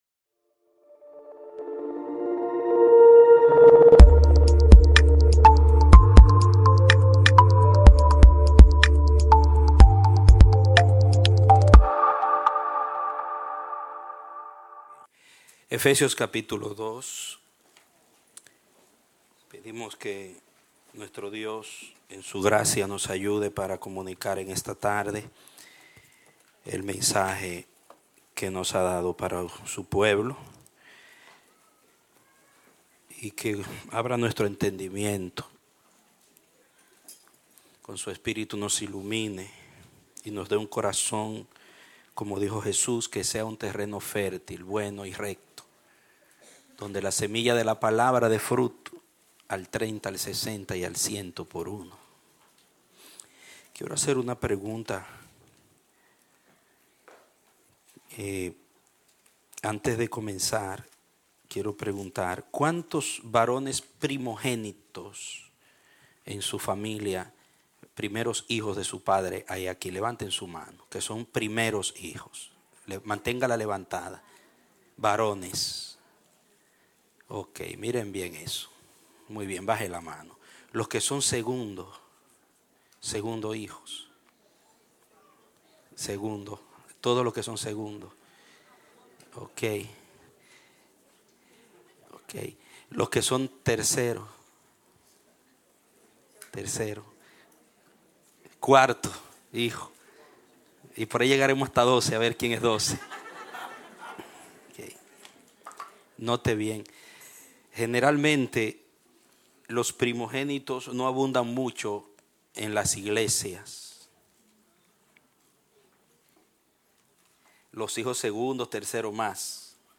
Un mensaje de la serie "Metástasis."